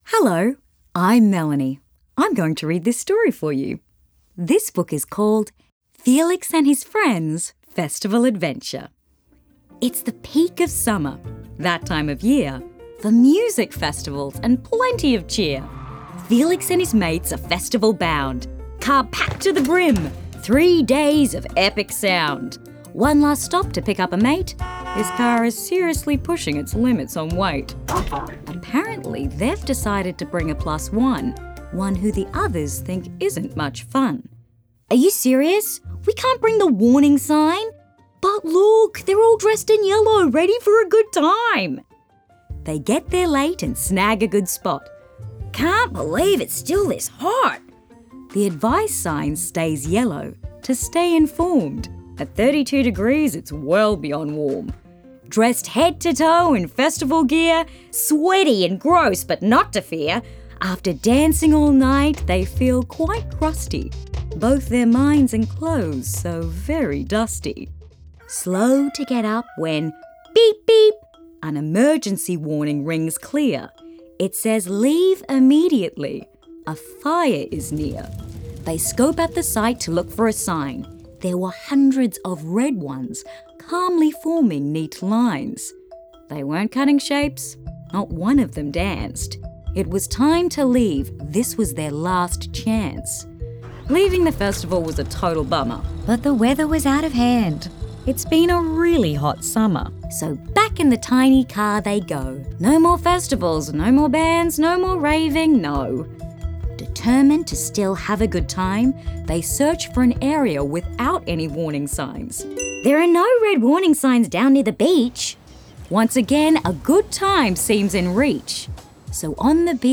Each story featured on this landing page is available with PDF with an accompanying audiobook that can be used together, or separately.
AWS-Festival_AudioBook.wav